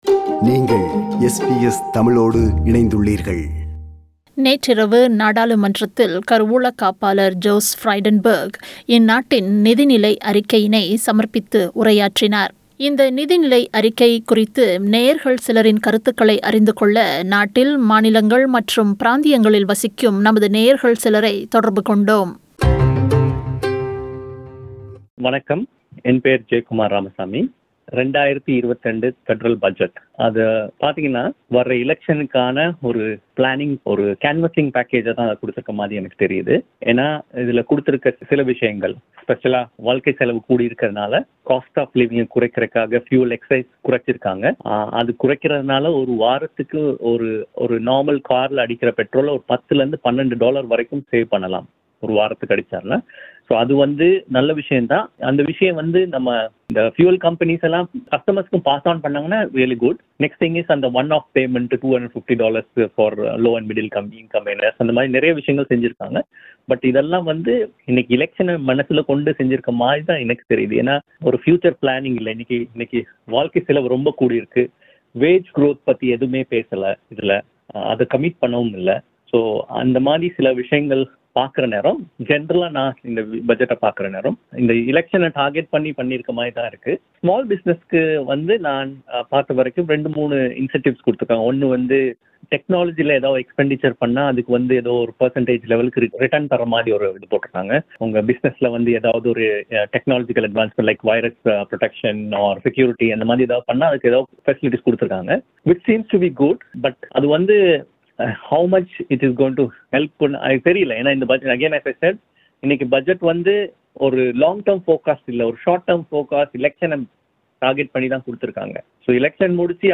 2022 Federal Budget : Voxpop
Few of our listeners are sharing their views about 2022-2023 federal budget